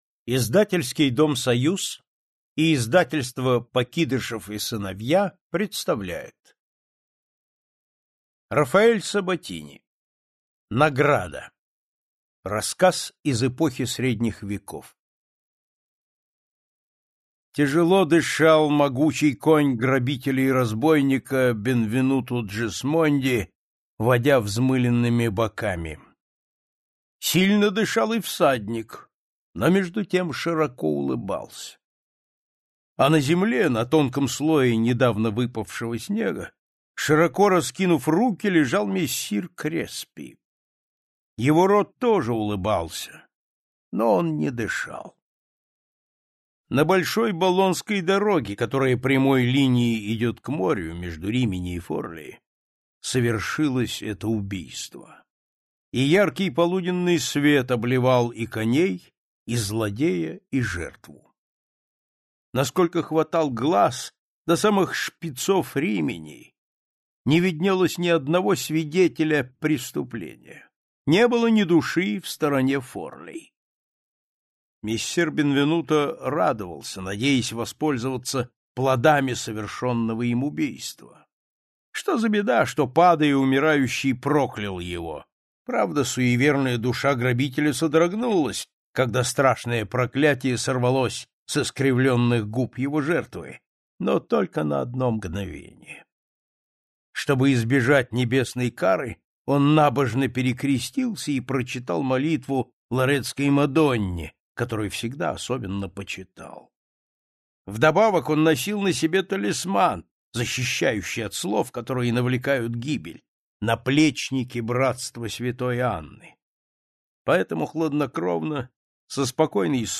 Аудиокнига Слово Борджиа. Сборник | Библиотека аудиокниг